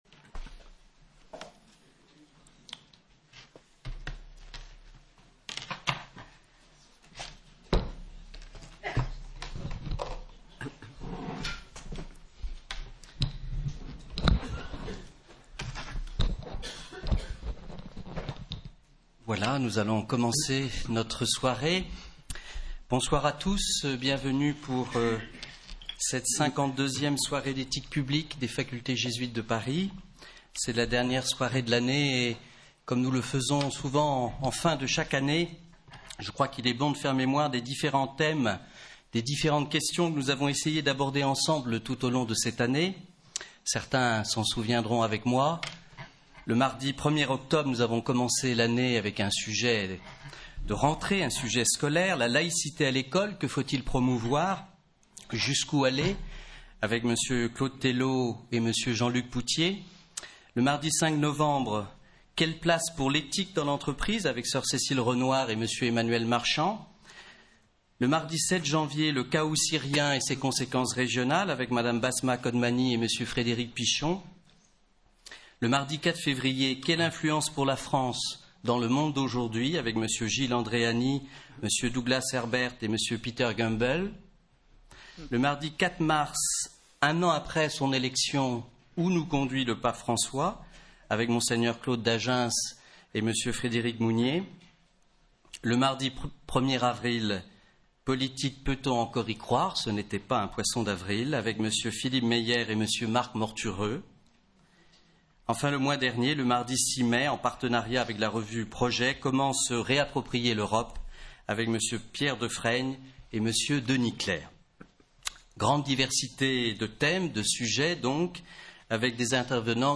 Réécoutez la conférence du mardi 3 juin avec Mme Hélène Carrère d'Encausse, de l'Académie française, et Bertrand Dufourcq.